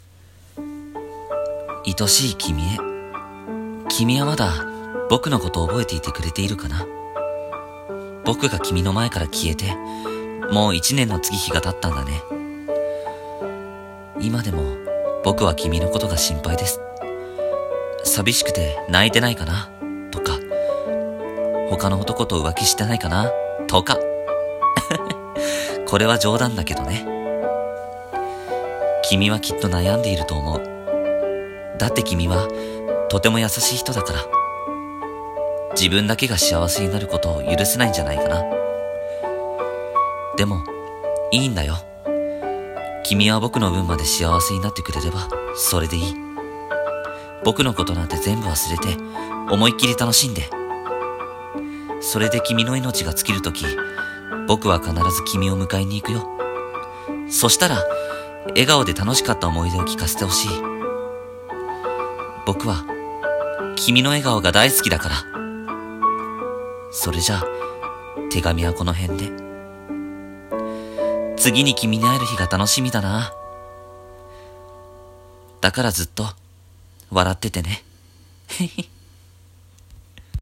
天国からの手紙【一人声劇】